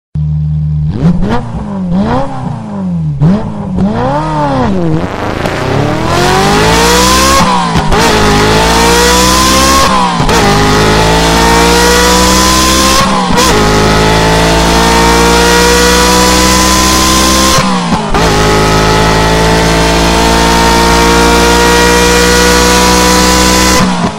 3D spatial surround sound "Racing motorcycle"
3D Spatial Sounds